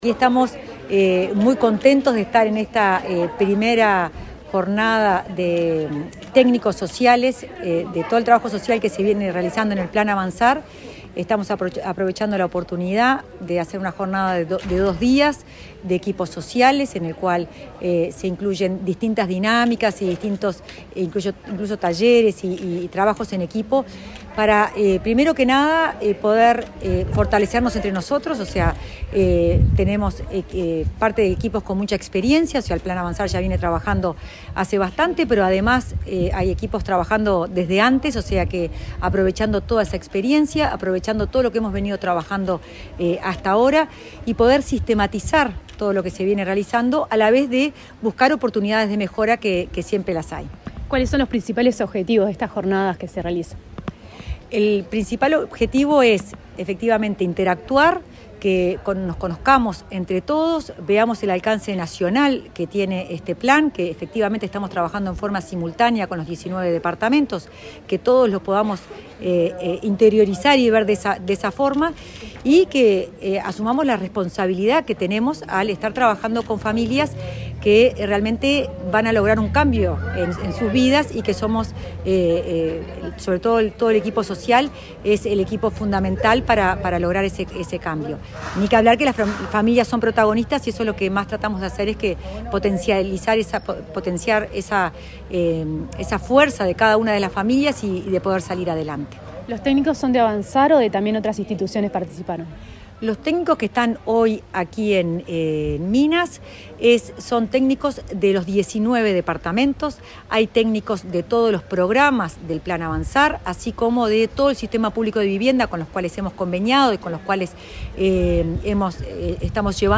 Entrevista a la directora de Integración Social y Urbana del Ministerio de Vivienda, Florencia Arbeleche
La directora de Integración Social y Urbana del Ministerio de Vivienda, Florencia Arbeleche, dialogó con Comunicación Presidencial en el departamento